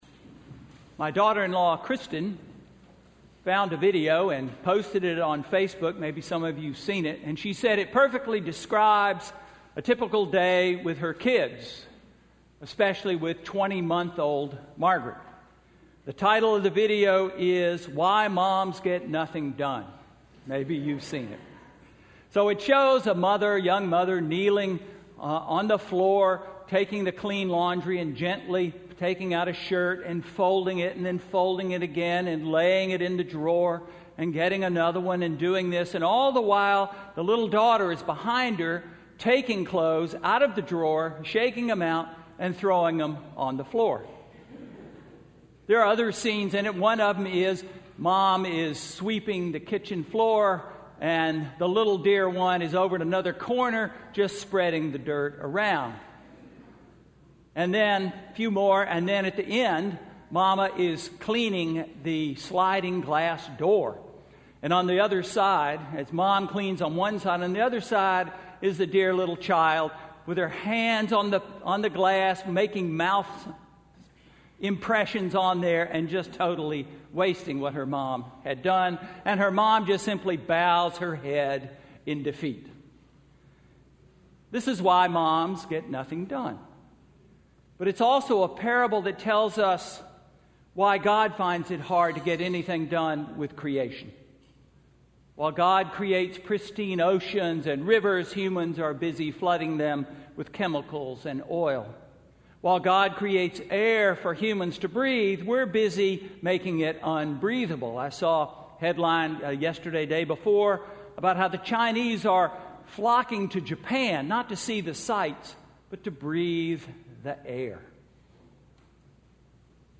Sermon–Lent 1–February 22, 2015